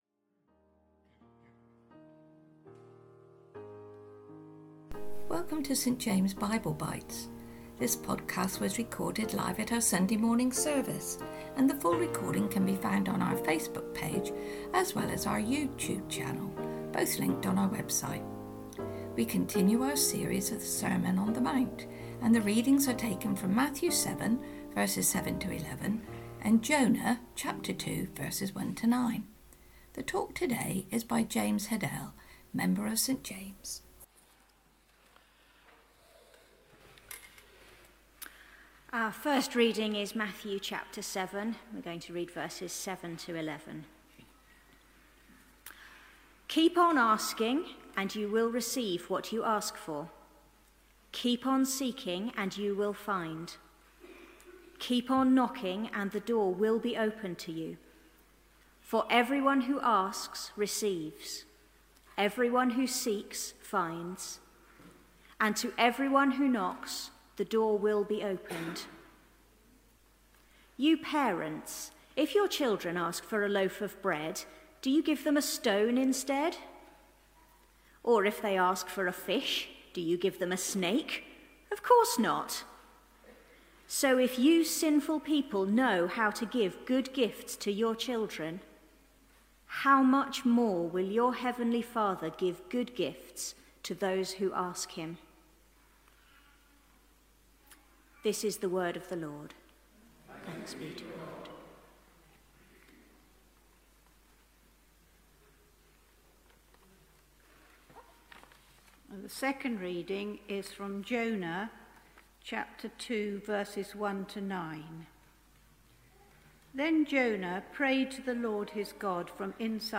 sermon-on-the-mount-ask-seek-knock